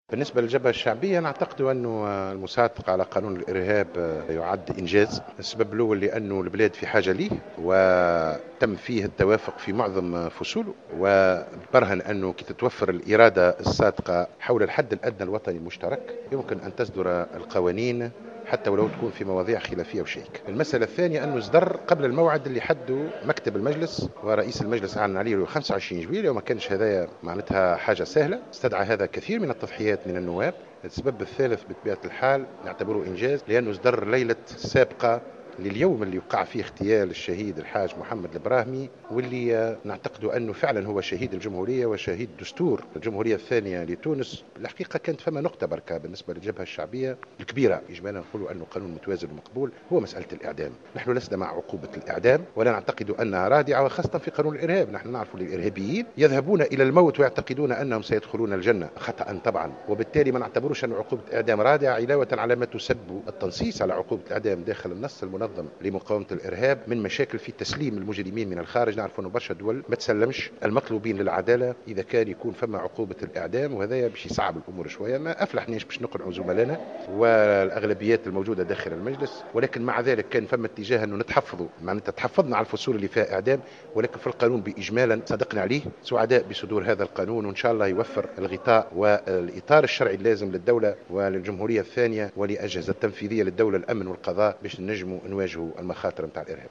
أكد القيادي في الجبهة الشعبية أحمد الصديق في تصريح لمراسلة جوهرة أف أم
خلال تدشين رواق بمجلس نواب الشعب